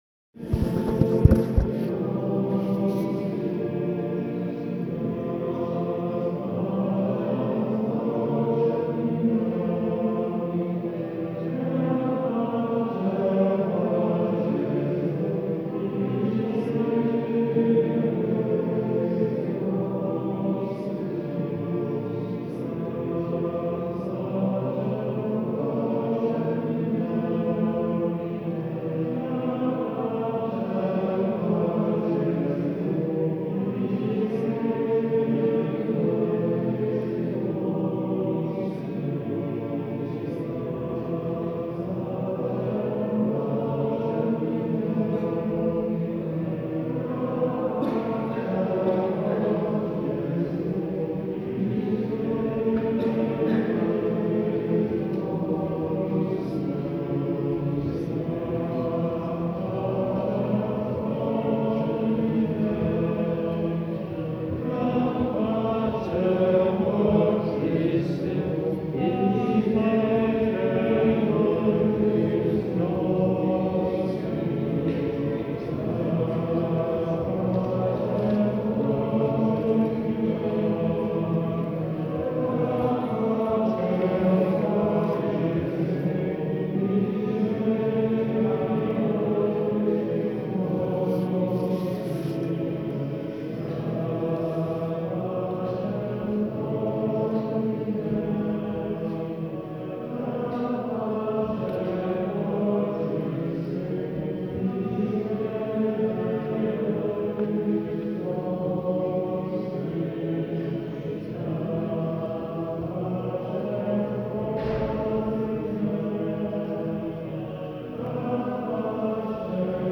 Da pacem… in diebus, en canon.
Ouïe: Le choeur des frères qui chantent Da pacem… in diebus.